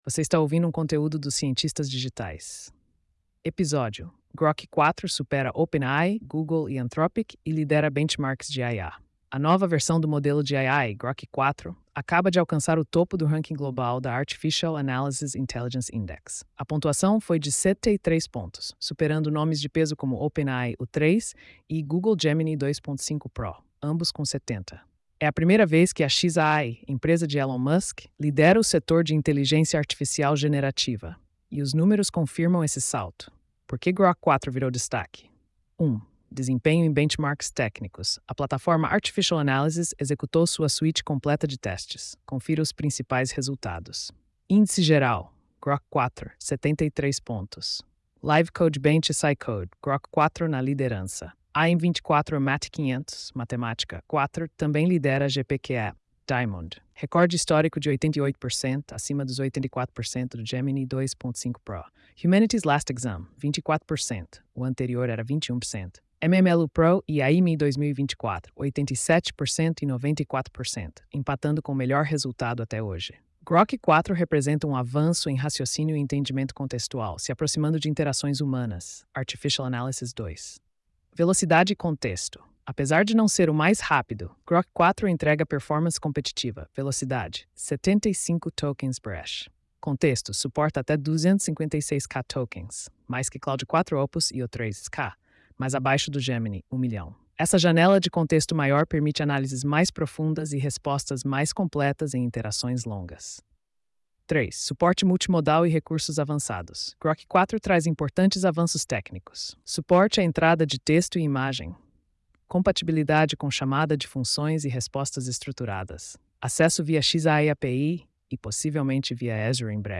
post-3324-tts.mp3